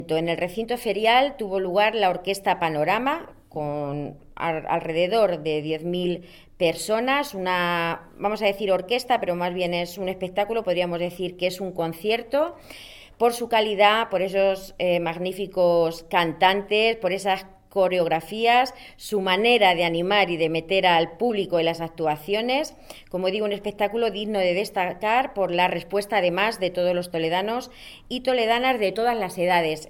María Teresa Puig, concejala de Festejos